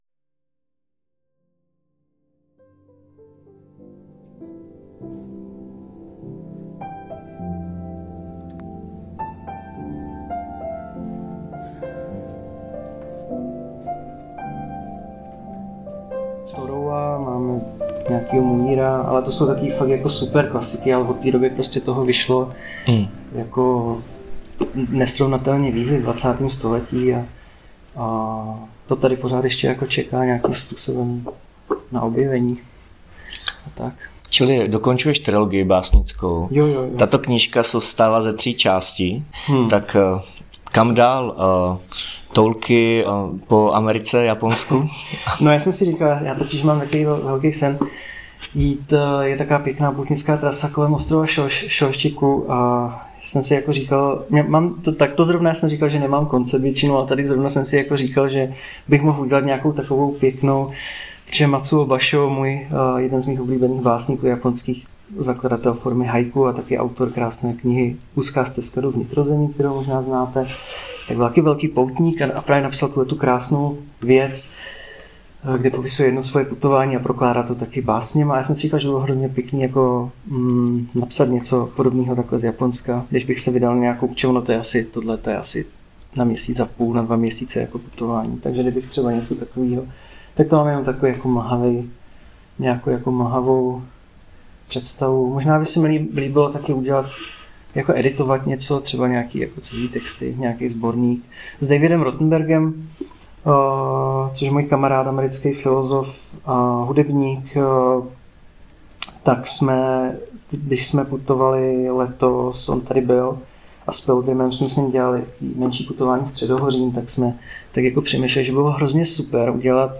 debata o knize Spolu
jsme hovořili na festivalu Knihy v Brně